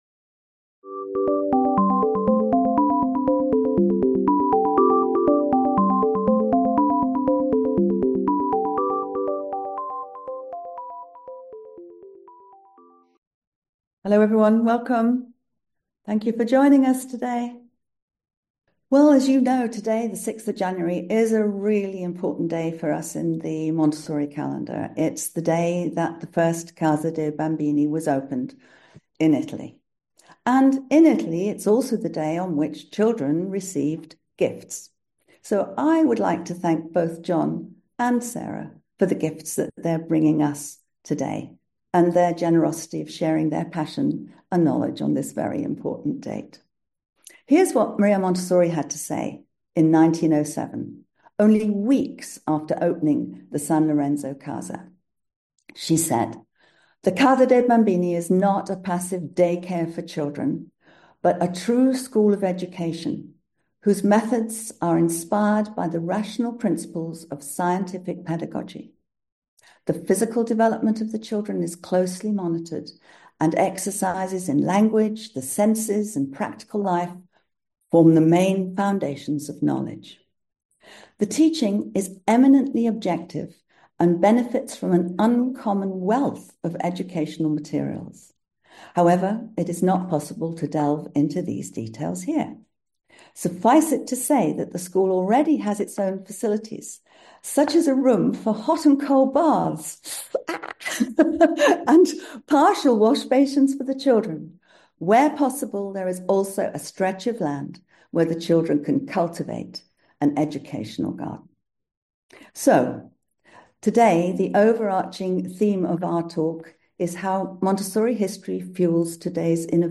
AMI Talk: How Montessori History Fuels Today’s Innovations in Education.